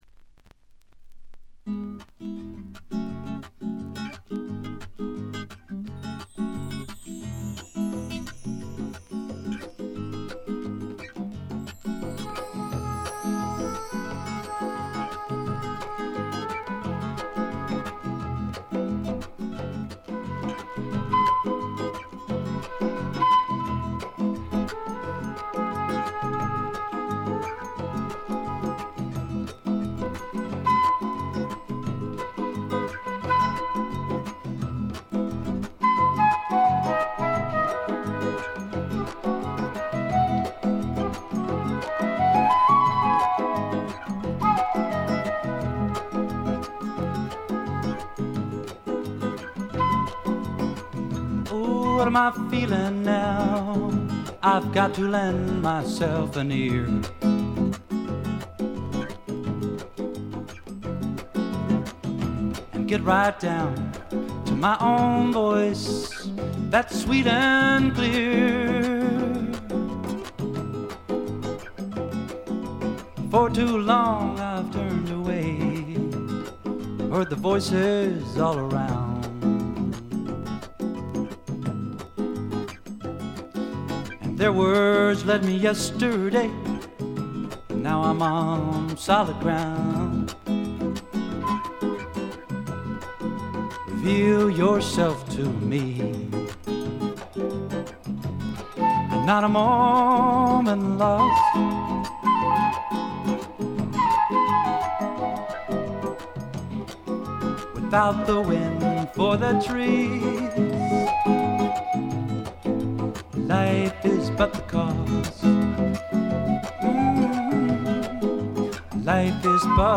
ところどころで軽微なチリプチ程度。
そこここにハワイ産アコースティック・グルーヴのような涼やかでちょっとトロピカルな気分が漂うあたりもいとをかし。
試聴曲は現品からの取り込み音源です。